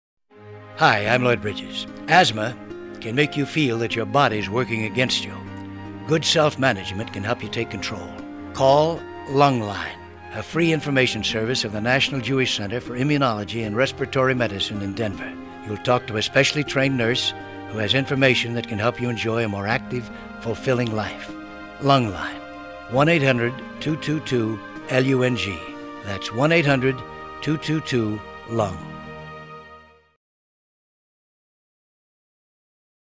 Celebrity PSAs